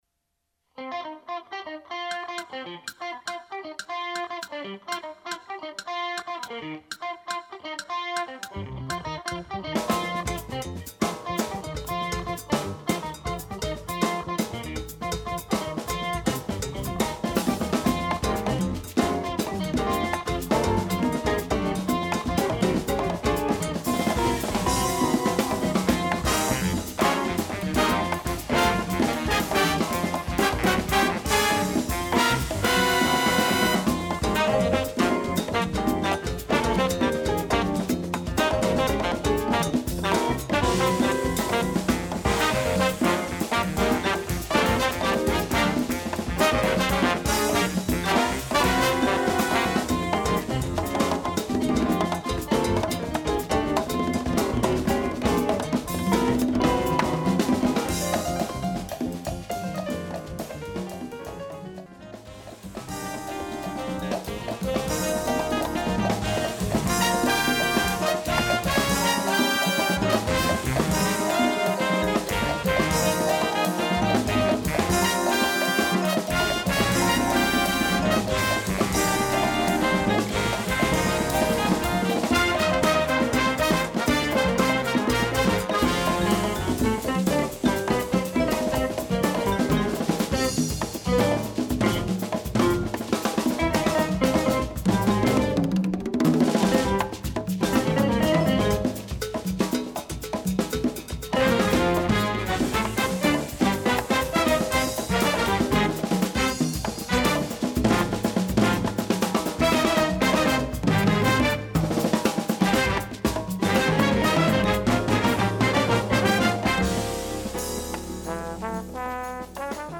Category: little big band
Style: mambo
Solos: trumpet 2, trombone, piano